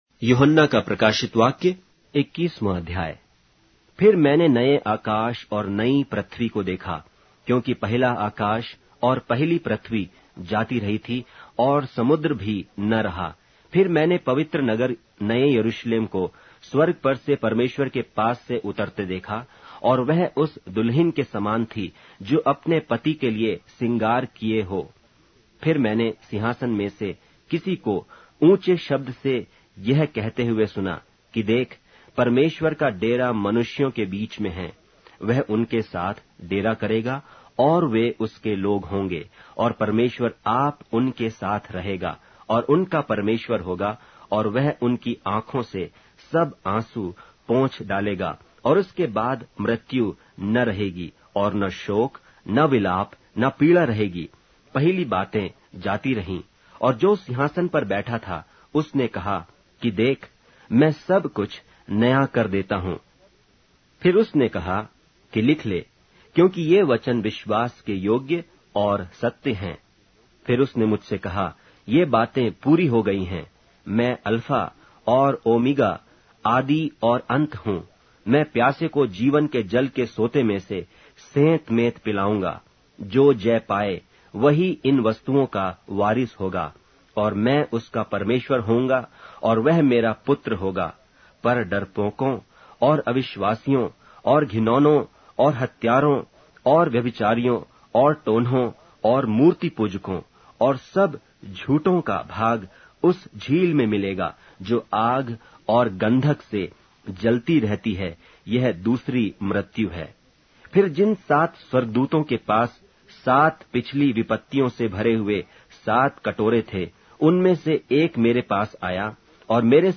Hindi Audio Bible - Revelation 22 in Tev bible version